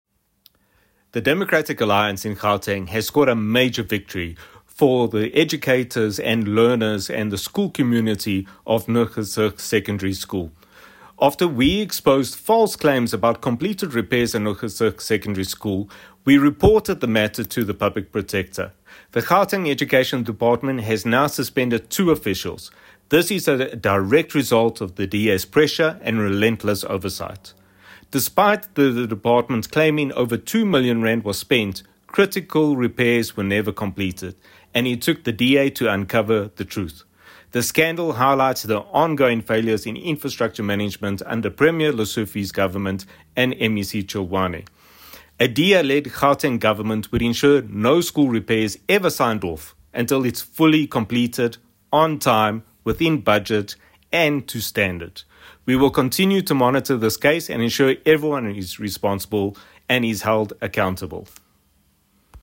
Issued by Sergio Isa Dos Santos MPL – DA Gauteng Shadow MEC for Education
soundbite by Sergio Isa Dos Santos MPL.